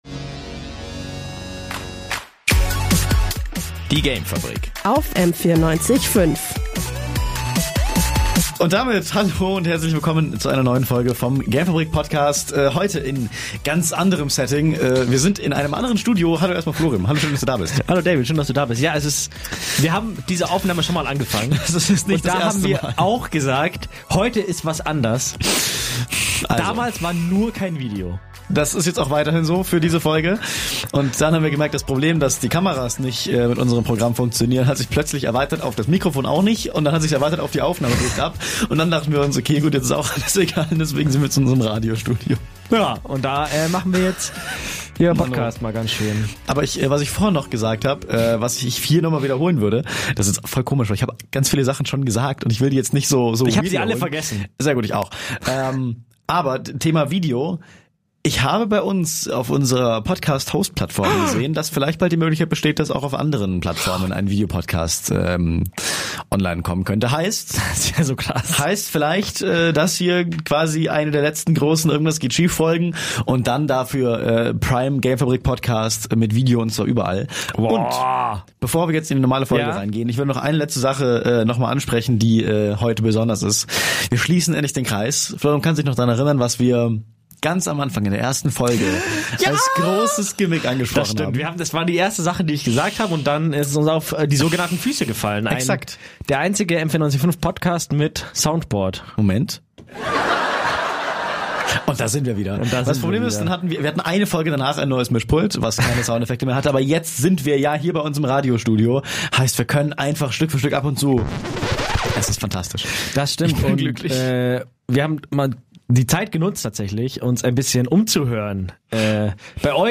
Dank technischem Großchaos sind wir drei Türen weiter in unser Radiostudio gezogen, deswegen könnt ihr uns heute leider nur zuhören, nicht zuschauen. Dafür wieder da: Das Soundboard!